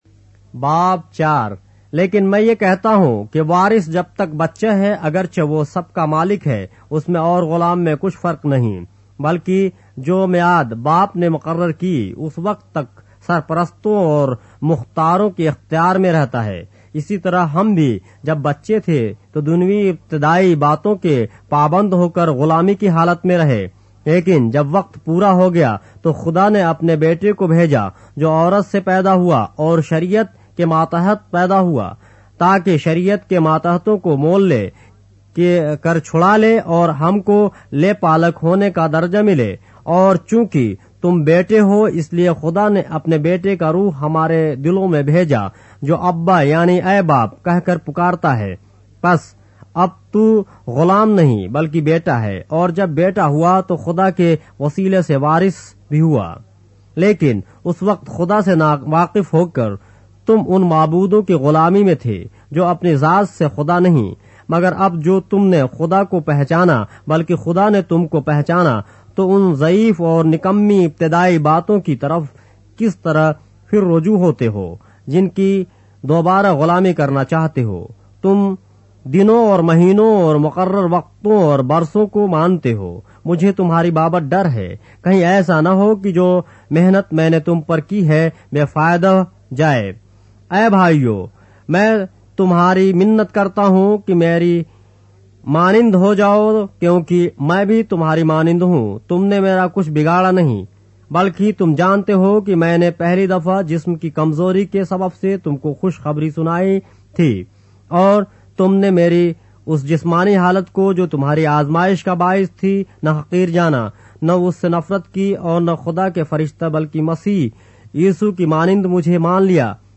اردو بائبل کے باب - آڈیو روایت کے ساتھ - Galatians, chapter 4 of the Holy Bible in Urdu